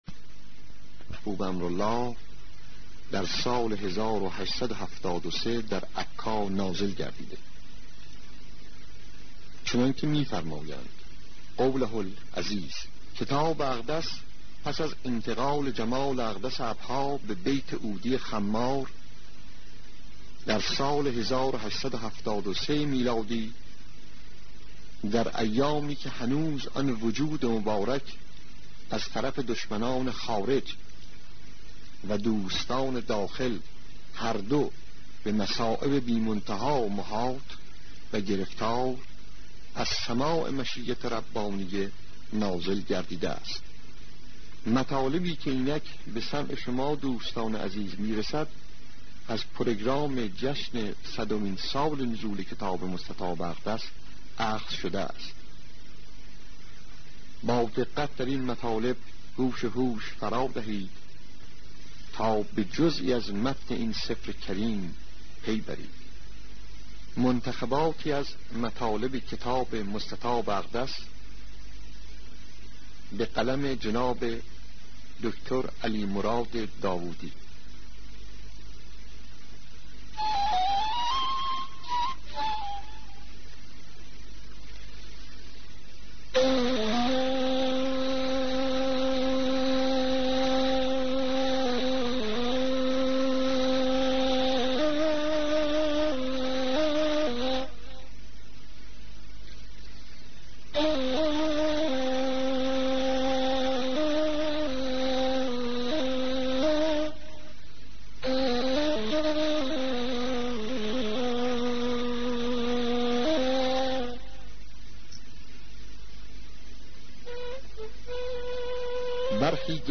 سخنرانی هایی پیرامون عقاید بهائی